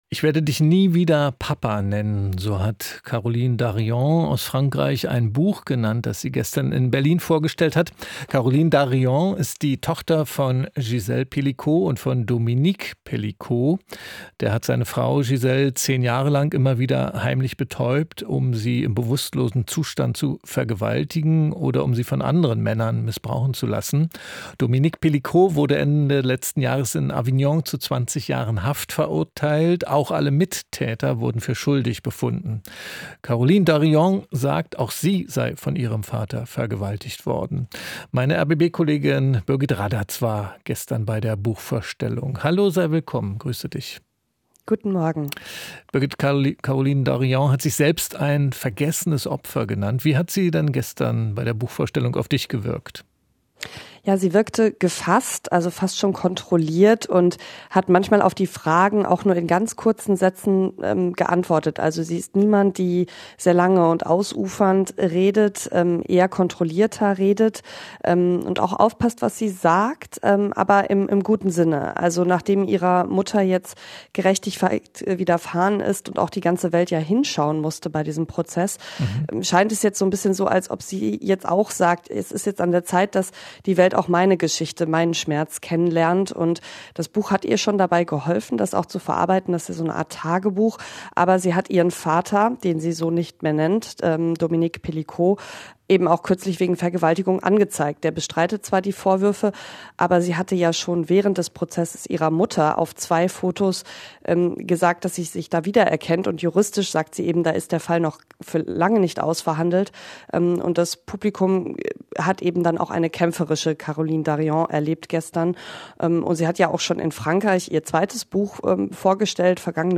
Lesung und Gespräch